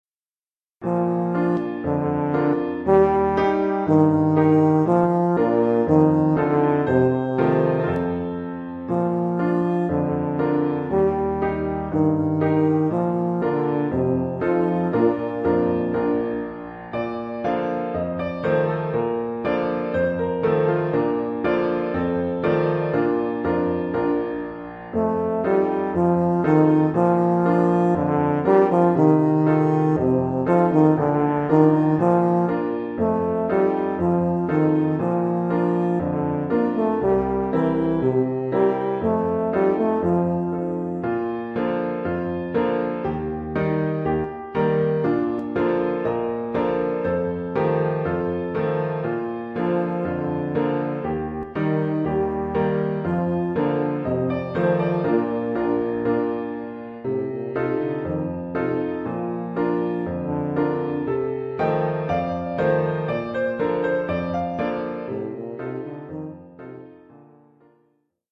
Oeuvre pour saxhorn basse / euphonium / tuba et piano.
Niveau : débutant (1er cycle, 1ère année).